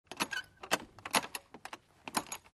Открытие замка с помощью ключа